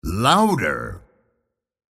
Louder audio that's louder than the other Louder audio from the Halo: Reach Clicktacular YouTube Masthead.